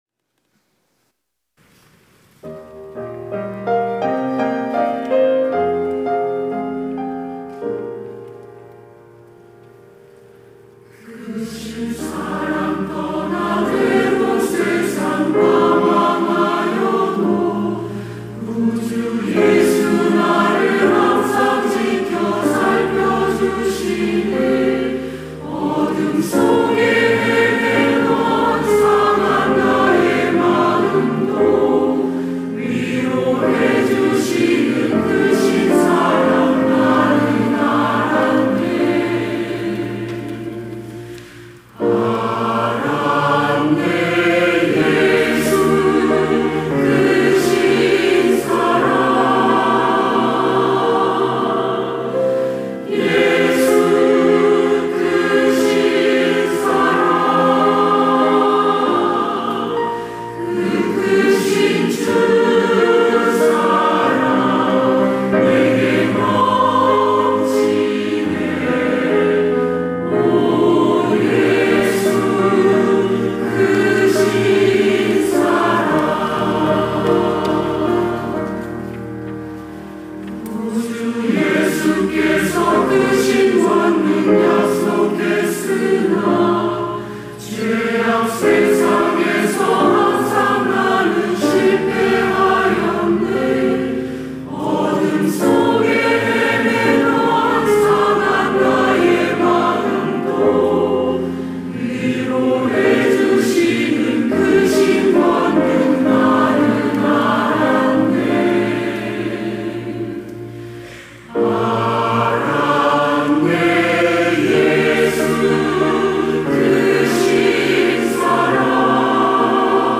시온(주일1부) - 주님은 항상
찬양대